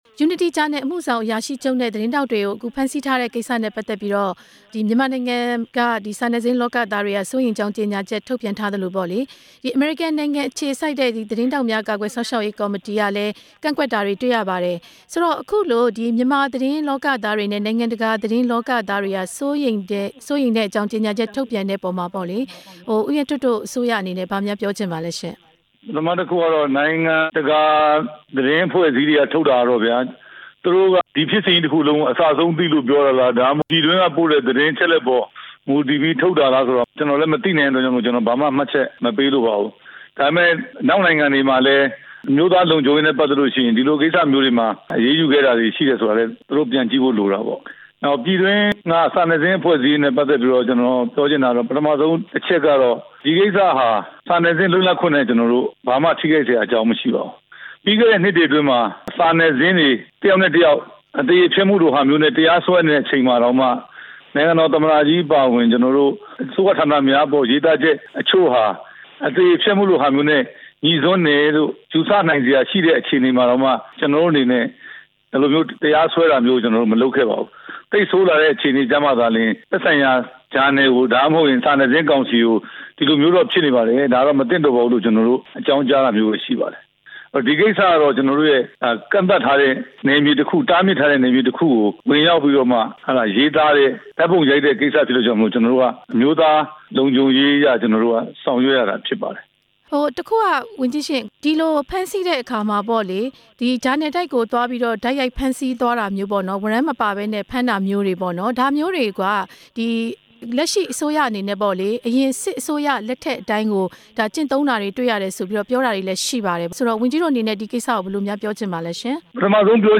ဦးရဲထွဋ်နဲ့ မေးမြန်းချက်